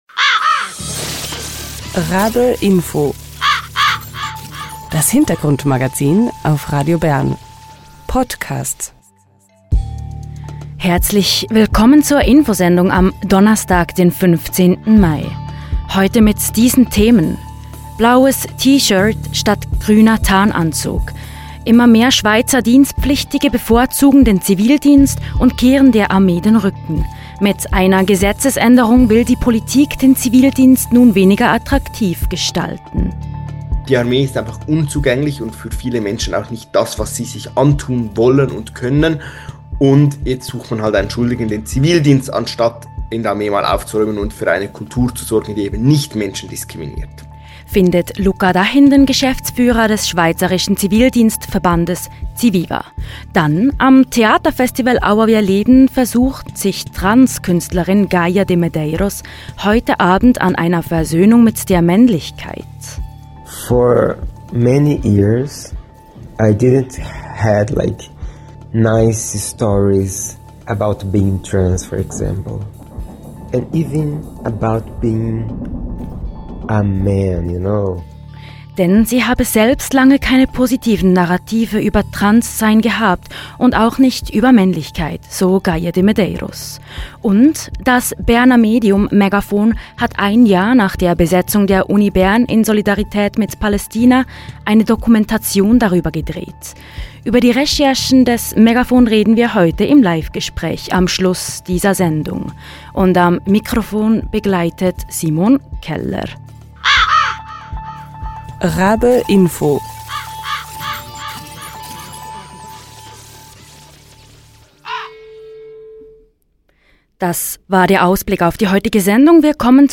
Das Berner Medium megafon hat ein Jahr nach der palästinasolidarischen Besetzung an der Uni Bern eine Dokumentation darüber gedreht. Über die Recherchen reden wir mit Vertreter*innen von megafon im Live-Gespräch.